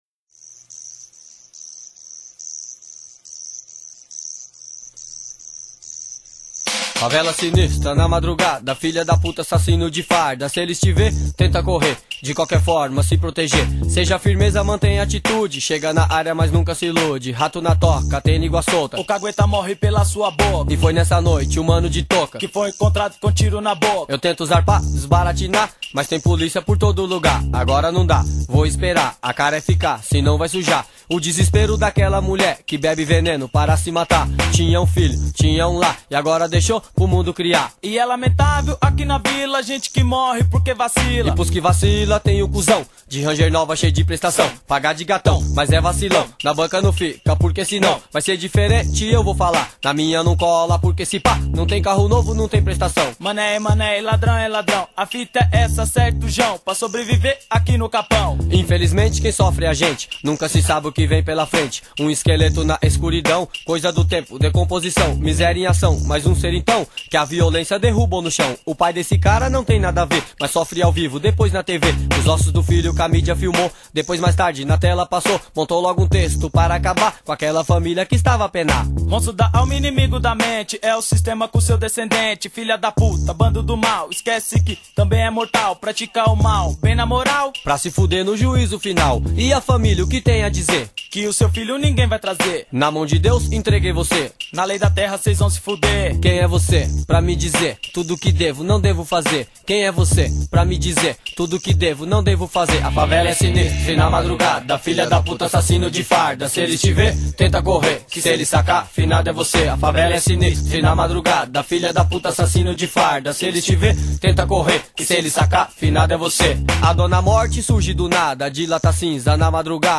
2024-05-10 21:32:35 Gênero: Rap Views